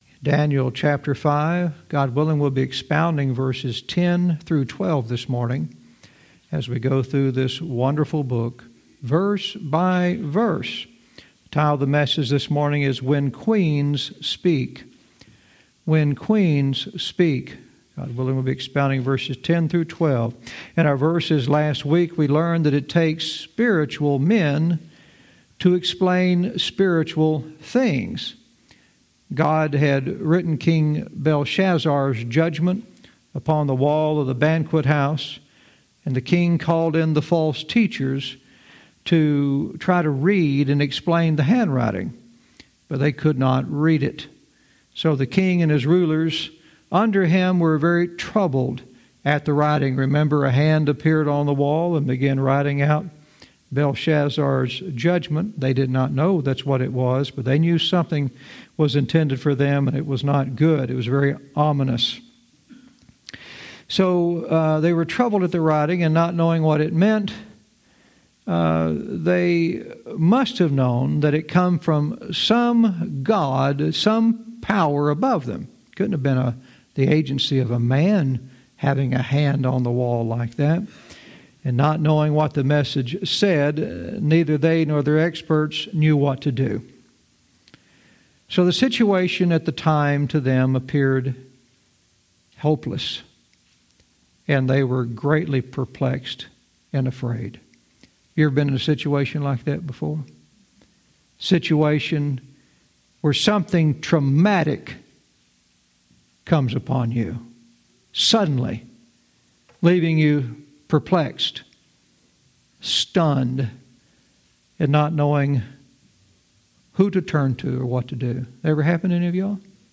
Verse by verse teaching - Daniel 5:10-12 "When Queens Speak"